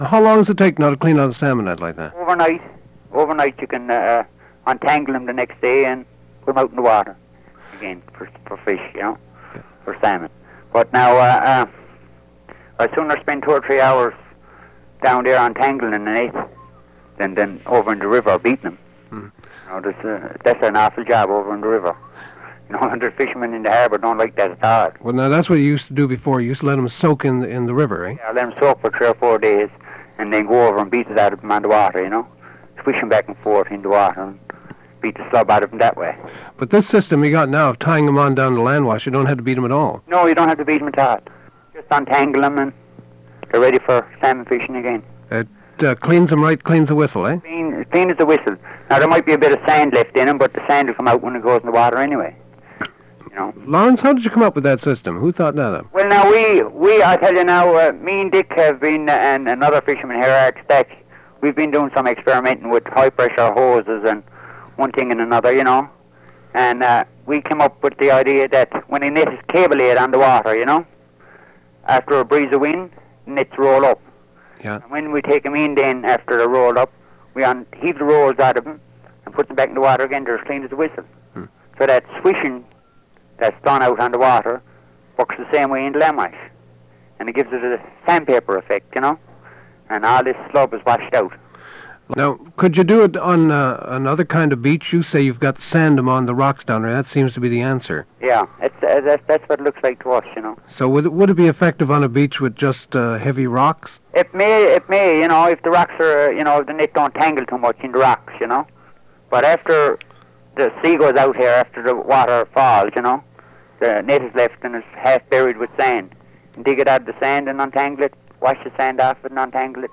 Dialects of English: Irish English volume 1 - The North of Ireland
Irish Avalon